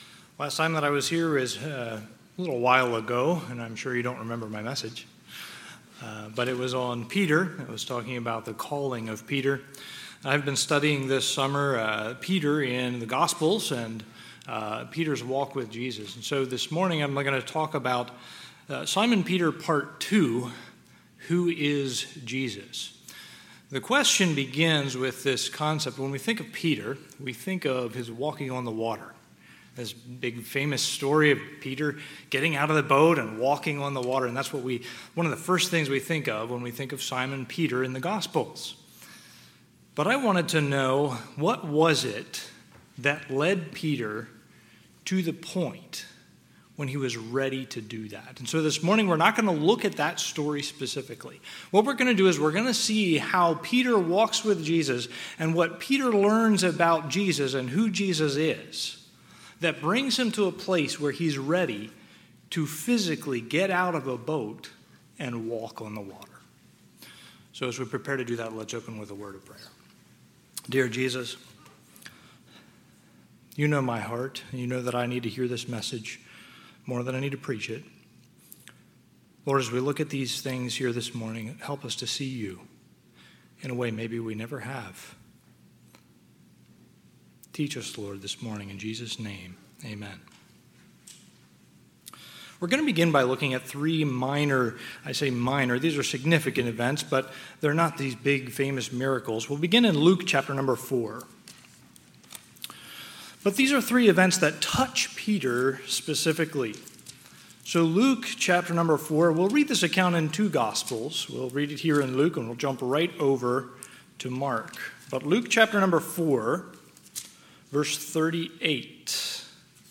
Sunday, June 23, 2024 – Sunday AM